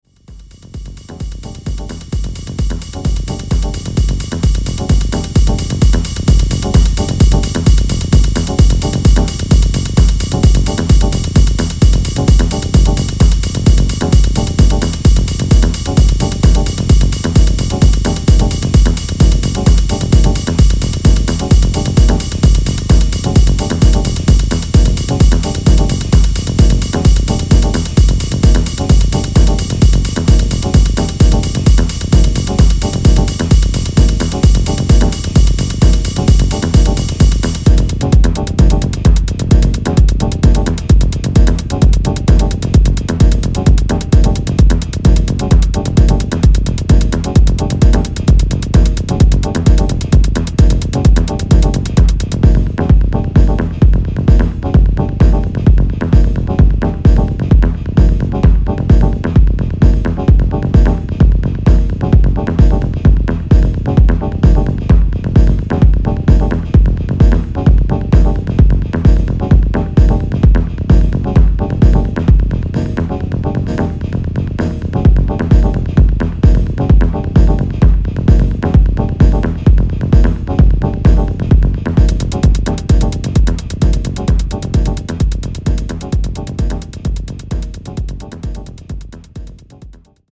Great underground house/techno hybrids.